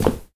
step0.wav